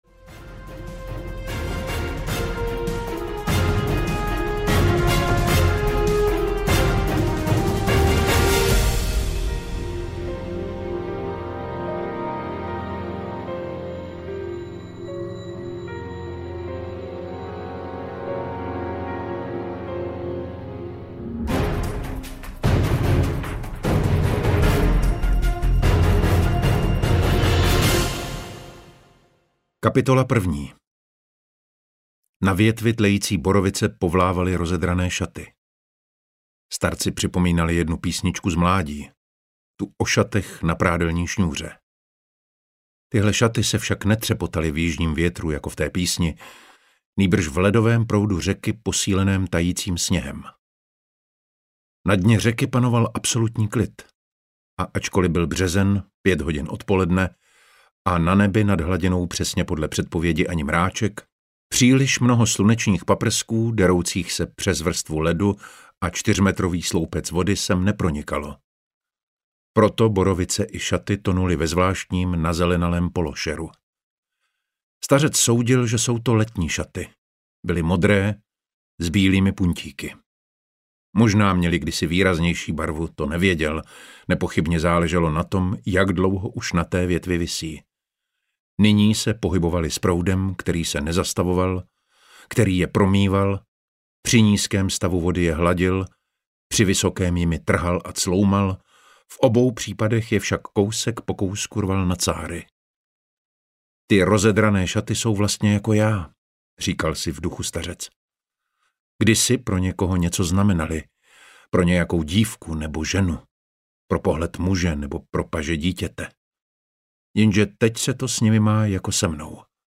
Nůž audiokniha
Ukázka z knihy